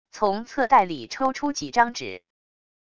从侧袋里抽出几张纸wav音频